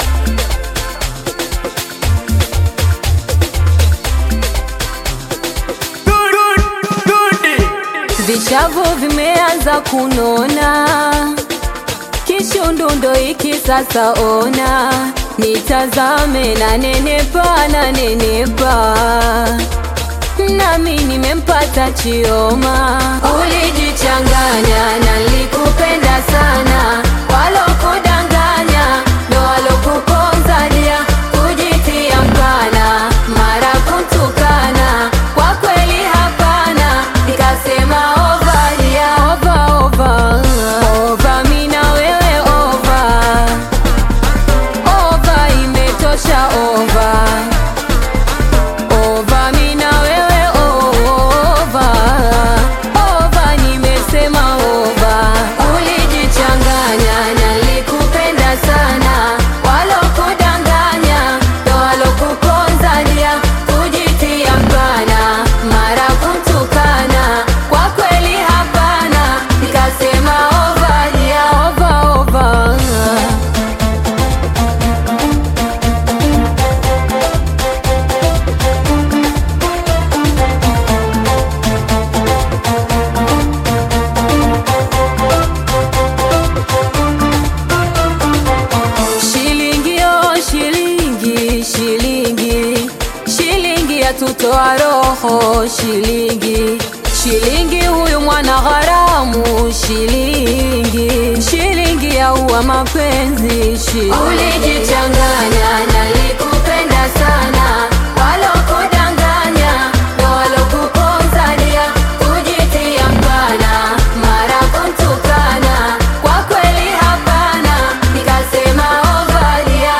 Tanzanian Bongo Flava artist, singer, and songwriter